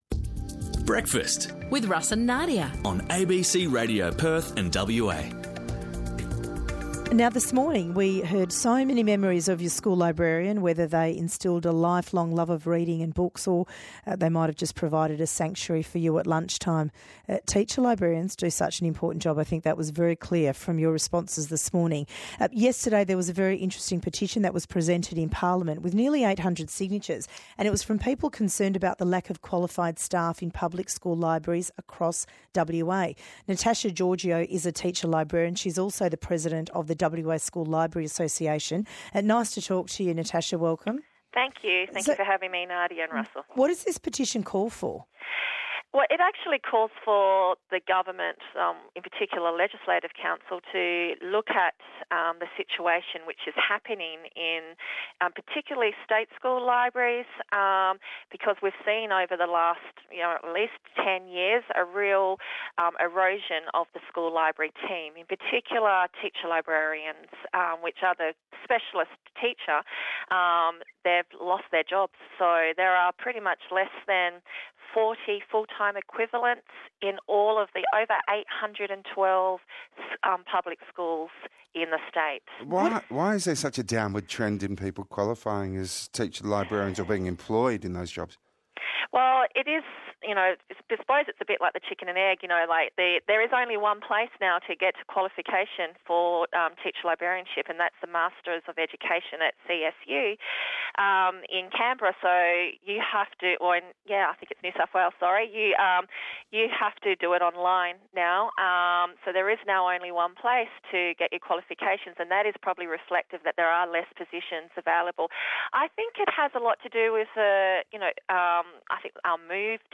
ABC-Radio-Perth-Teacher-Librarian-Petition.mp3